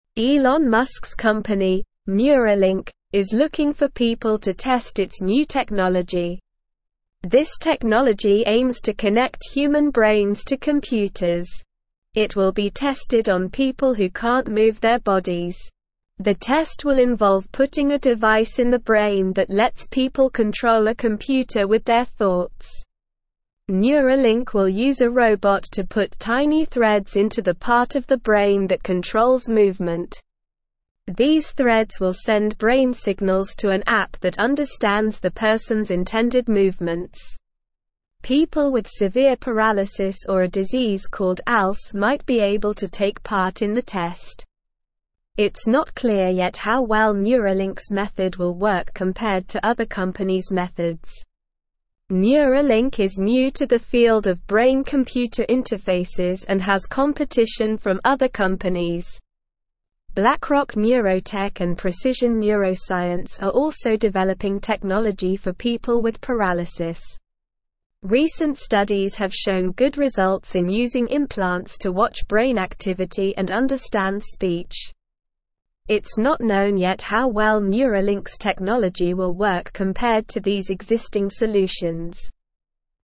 Slow
English-Newsroom-Lower-Intermediate-SLOW-Reading-New-Brain-Tool-Help-People-Move-Computers-with-Thoughts.mp3